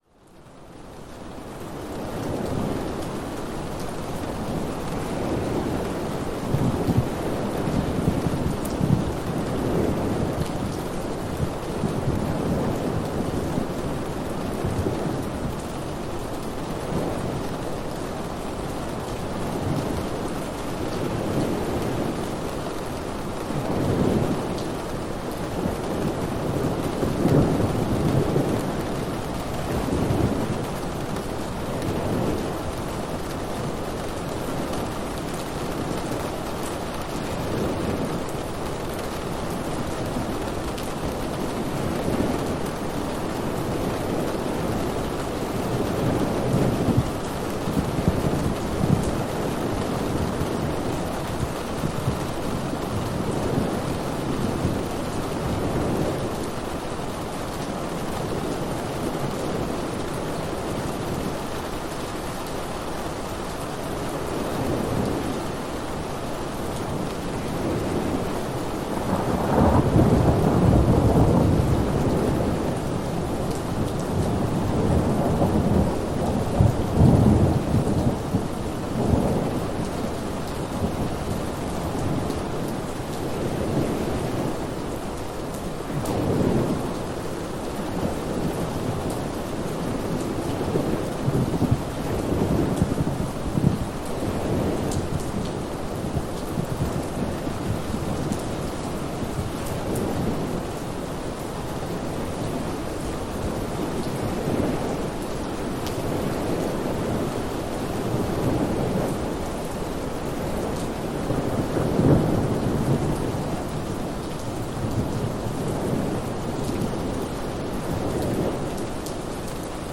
Tormenta Suave de Verano para un Descanso que Acaricia el Alma
Lluvia Tranquila, Lluvia de Fondo, Sonido de Tormenta, Día Lluvioso, Lluvia Para Soñar